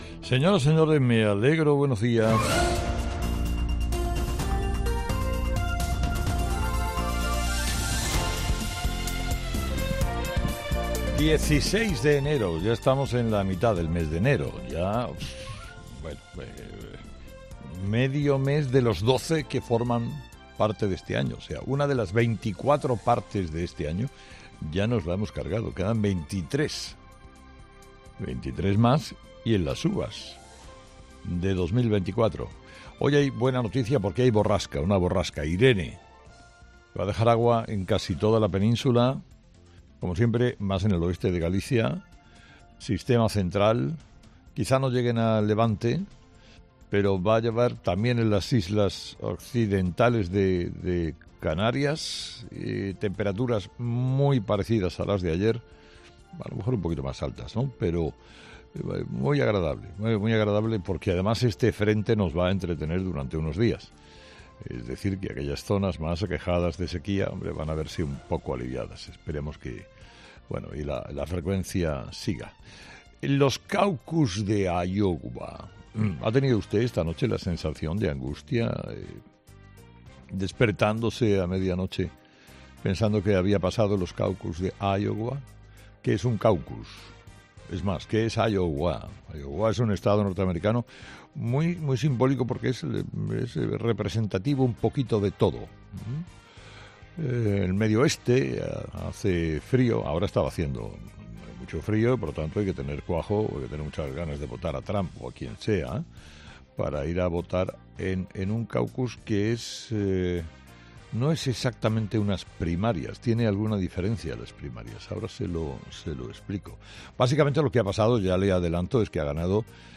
Carlos Herrera, director y presentador de 'Herrera en COPE', comienza el programa de este lunes analizando las principales claves de la jornada que pasan, entre otras cosas, por las enmiendas parciales a la ley de amnistía.